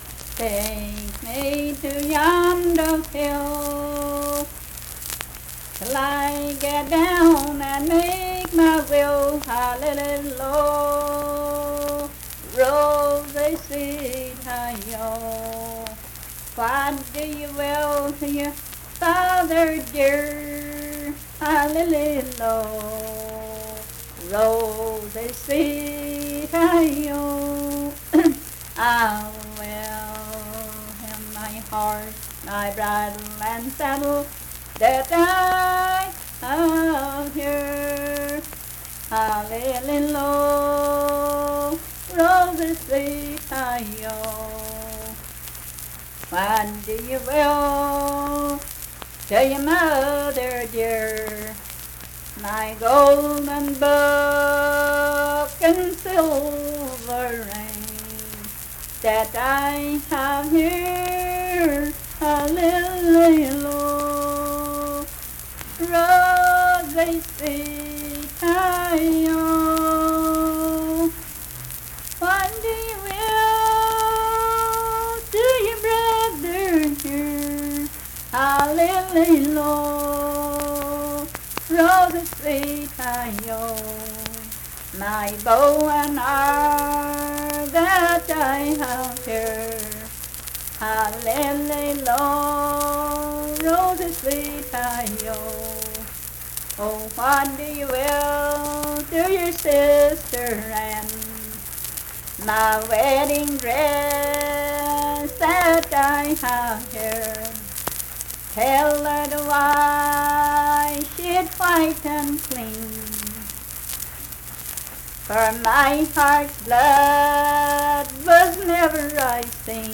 Unaccompanied vocal music
Verse-refrain 8(3-5w/R).
Performed in Big Creek, Logan County, WV.
Voice (sung)